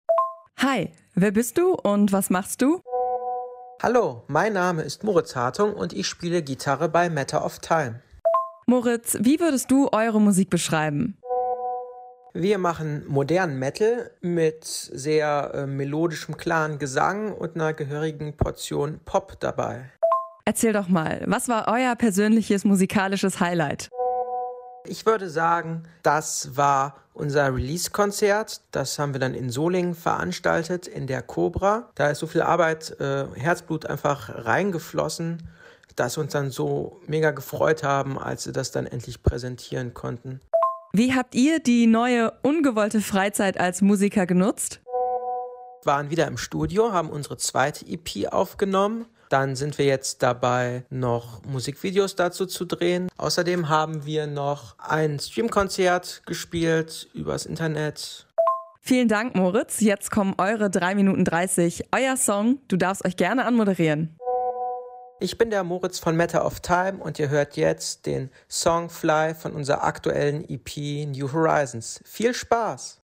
Metalband